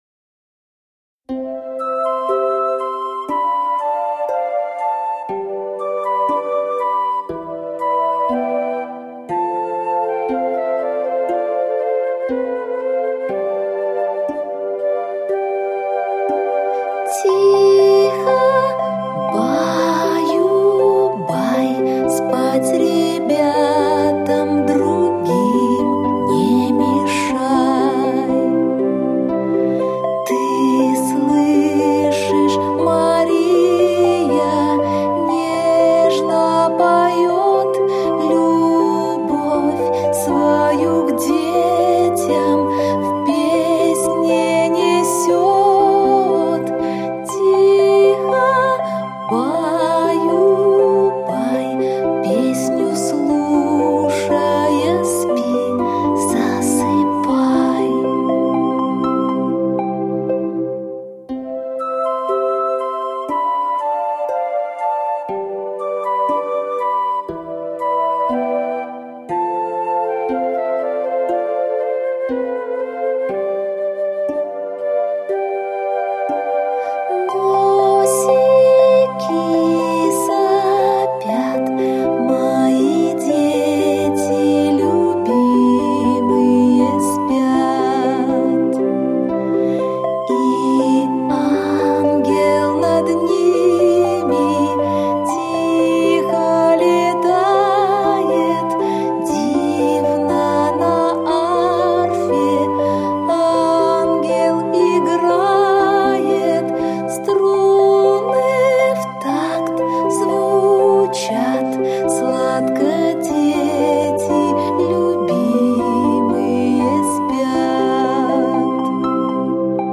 Аудиокнига 7 вечеров со сказкой. Старинные сказки и колыбельные | Библиотека аудиокниг